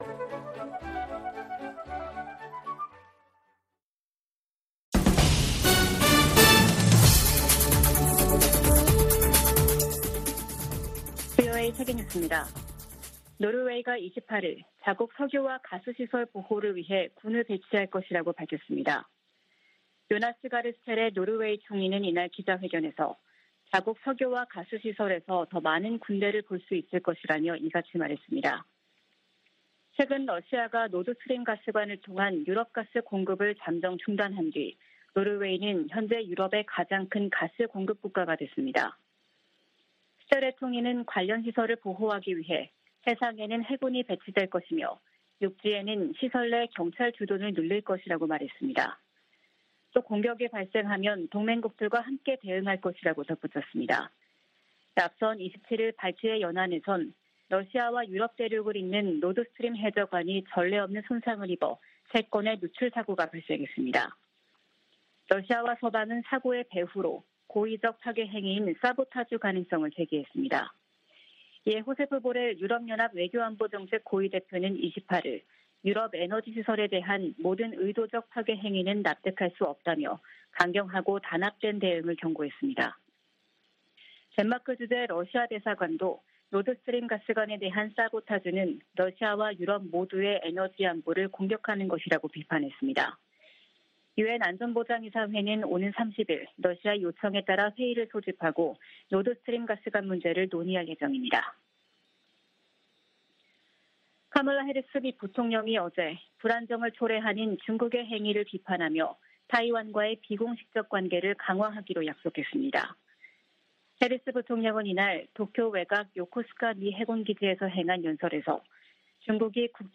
VOA 한국어 아침 뉴스 프로그램 '워싱턴 뉴스 광장' 2022년 9월 29일 방송입니다. 북한이 동해상으로 미상의 탄도미사일을 발사했다고 한국 합동참모본부가 밝혔습니다. 카멀라 해리스 부통령이 도쿄에서 한국 국무총리와 만나 북핵위협 해결을 위한 협력을 약속했습니다. 해리스 부통령이 한반도 비무장지대를 방문하는 건 방위 공약을 최고위급에서 재확인하는 것이라고 미국 전문가들이 진단했습니다.